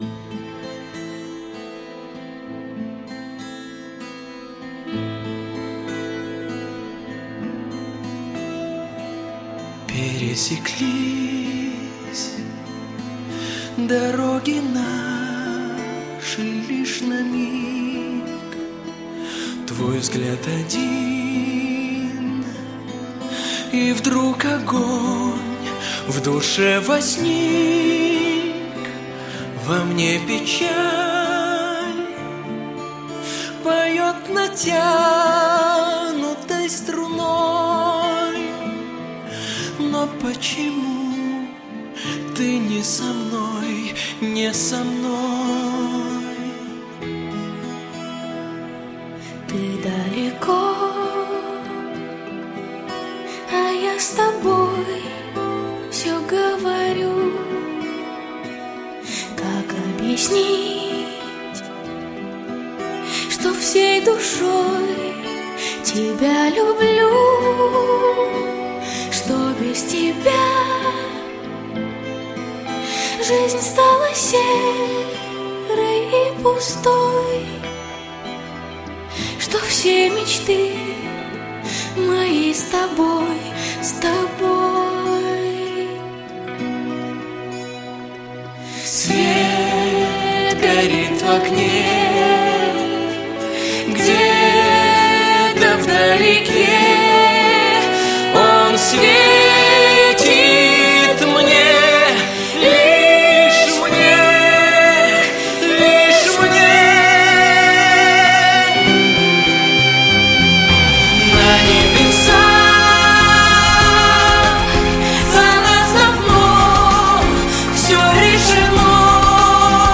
Я знала, что песня не та, посто тут - дуэт...